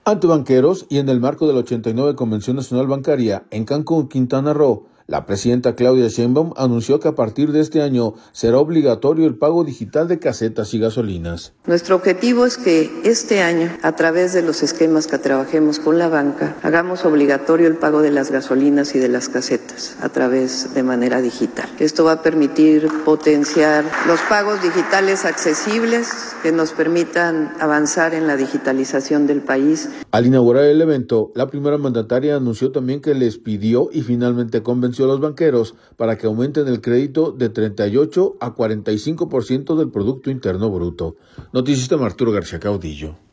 Ante banqueros y en el marco de la 89 Convención Nacional Bancaria, en Cancún, Quintana Roo, la presidenta Claudia Sheinbaum, anunció que a partir de este año será obligatorio el pago digital de casetas y gasolinas.